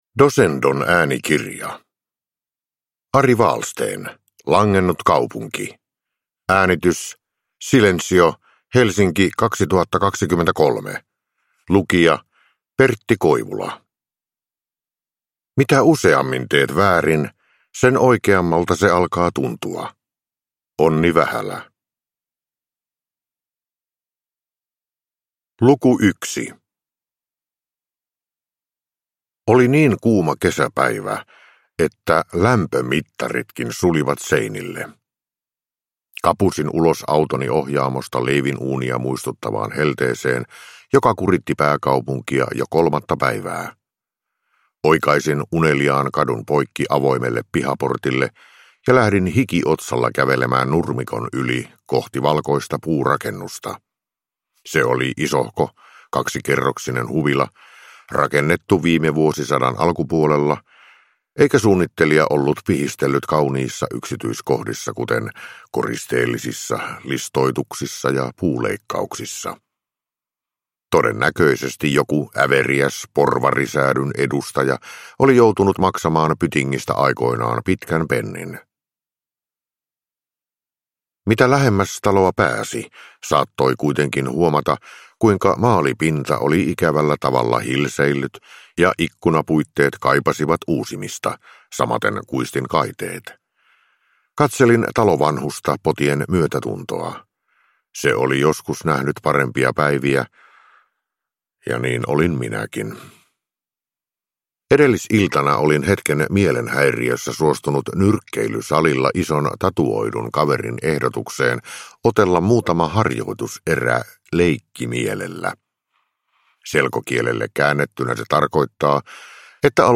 Langennut kaupunki – Ljudbok – Laddas ner